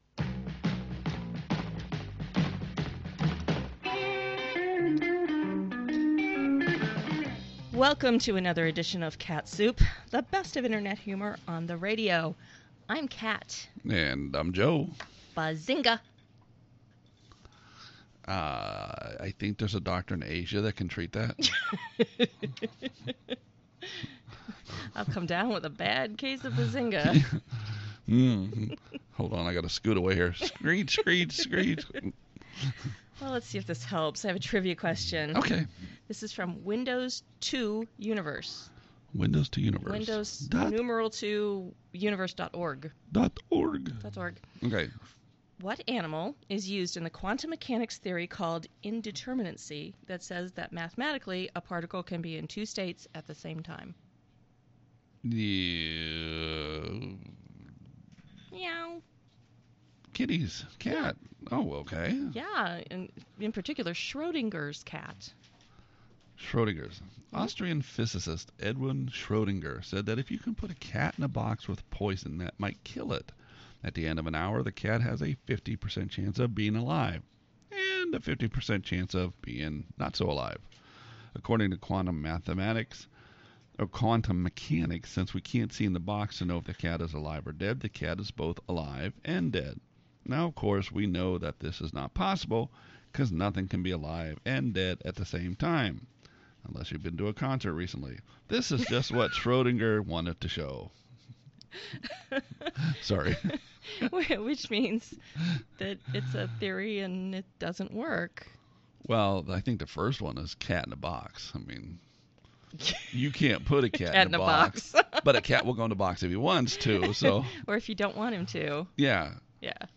You IMPROVISE. You tell stories. You sing.